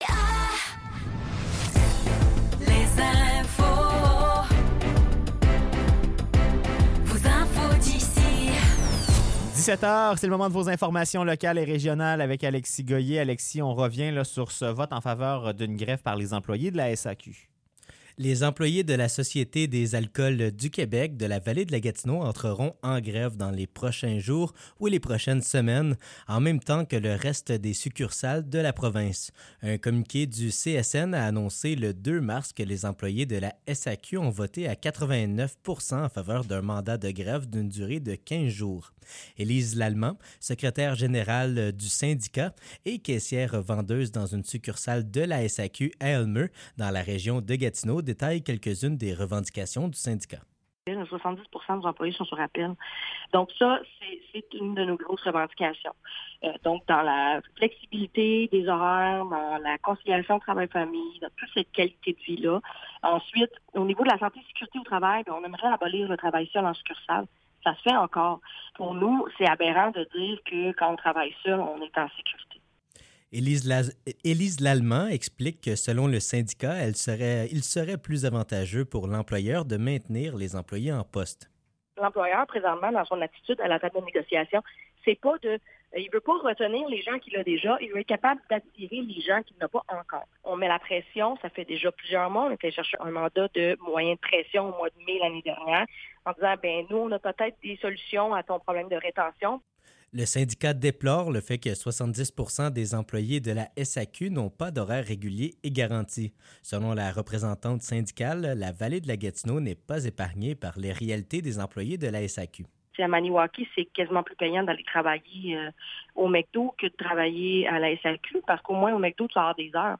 Nouvelles locales - 7 mars 2024 - 17 h